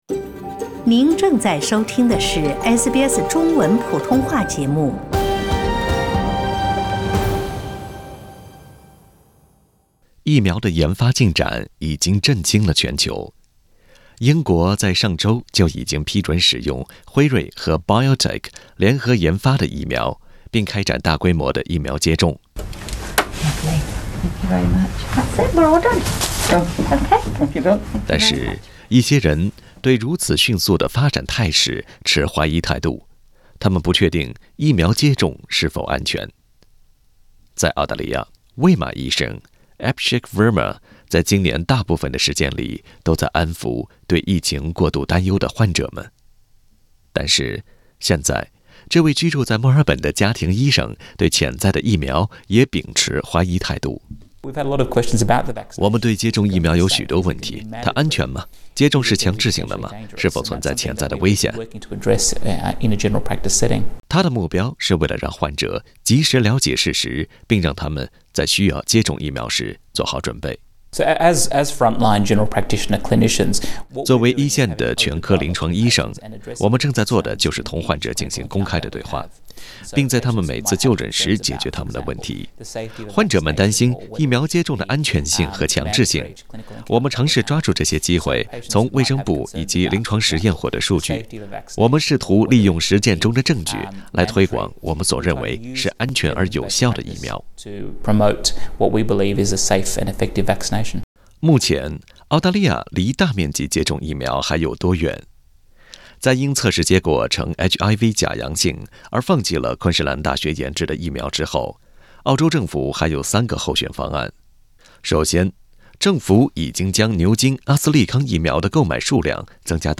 欢迎点击图片音频，收听完整的报道。